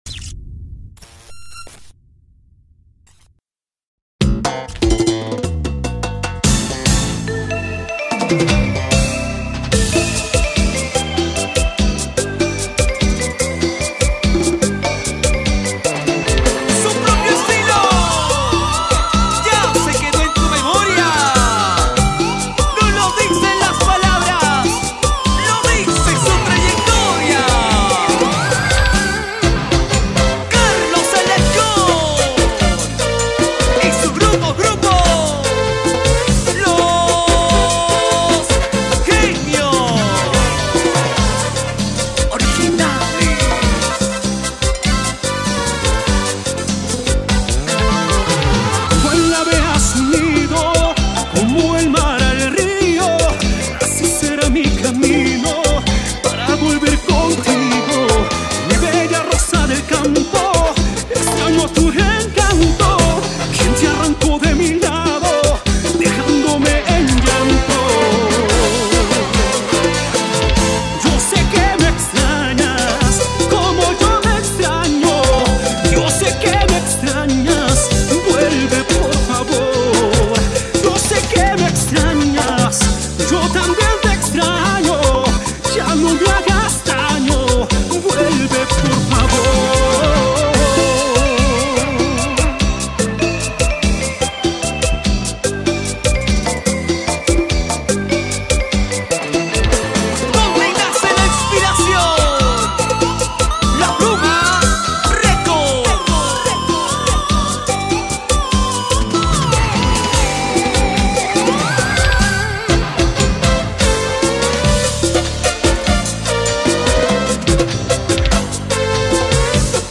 CUMBIA